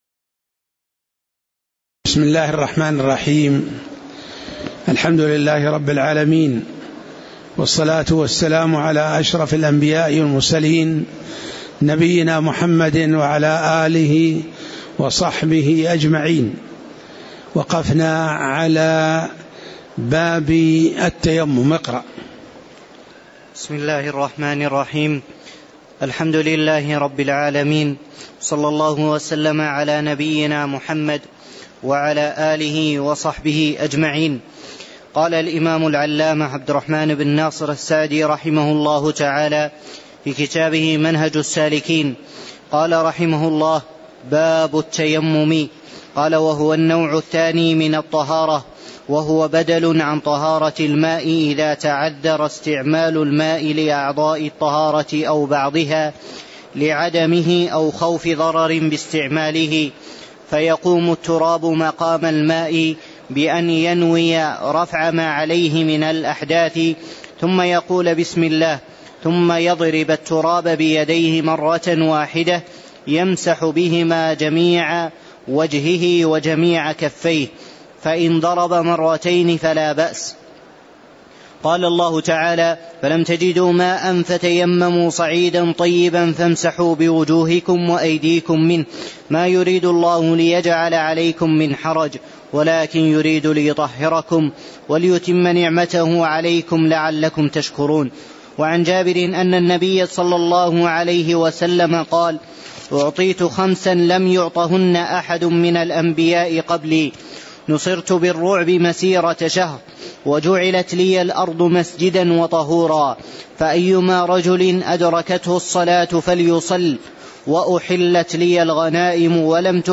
تاريخ النشر ١٣ شوال ١٤٣٧ هـ المكان: المسجد النبوي الشيخ